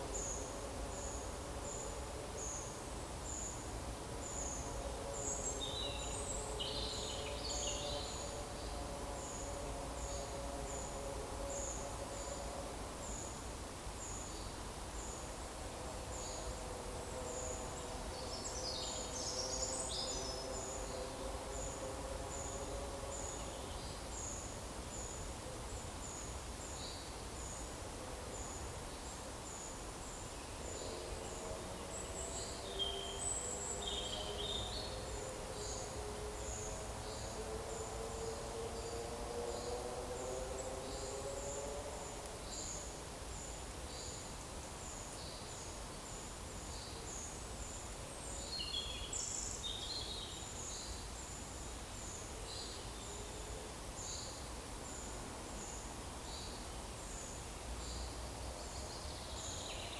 Monitor PAM
Certhia familiaris
Certhia brachydactyla
Columba oenas
Erithacus rubecula
Turdus iliacus